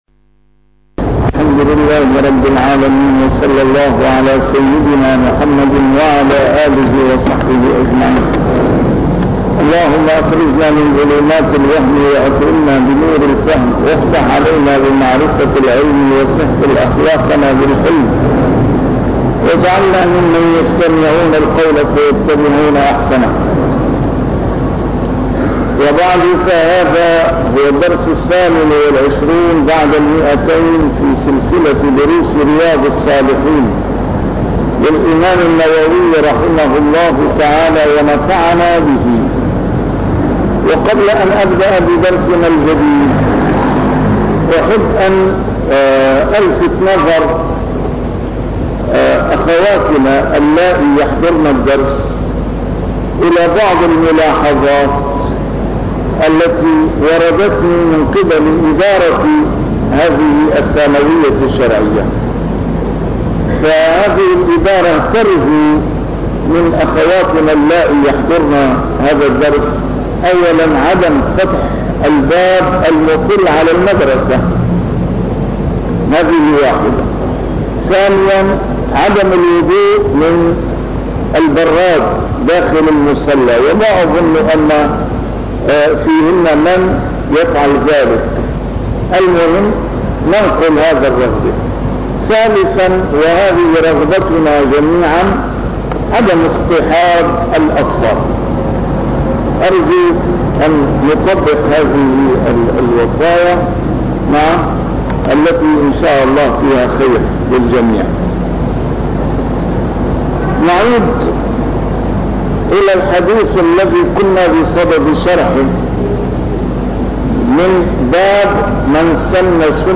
A MARTYR SCHOLAR: IMAM MUHAMMAD SAEED RAMADAN AL-BOUTI - الدروس العلمية - شرح كتاب رياض الصالحين - 228- شرح رياض الصالحين: فيمن سنَّ سنّةً